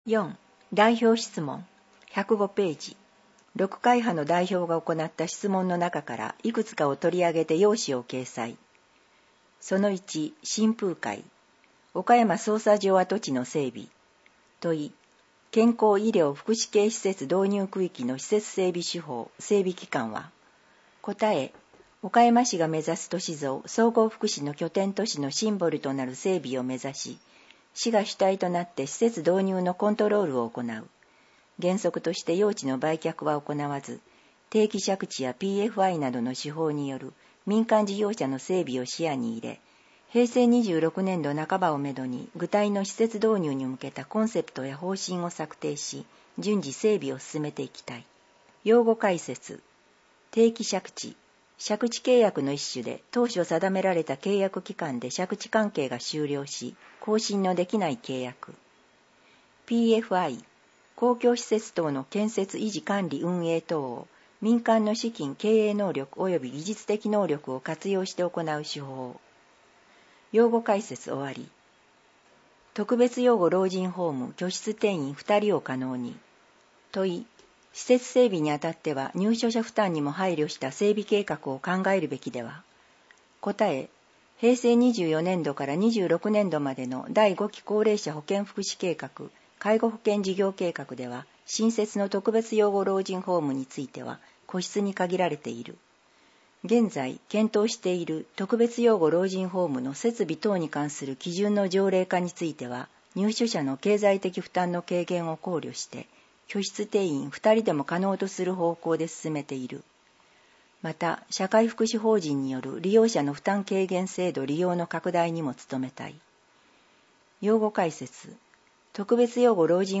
また、「岡山市議会だより」を音声でお聞きいただけます。声の市議会だより（音声版「岡山市議会だより」）は、ボランティア「岡山市立図書館朗読奉仕の会」のご協力により作成しています。